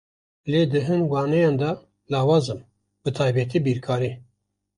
Pronounced as (IPA)
/biːɾkɑːˈɾiː/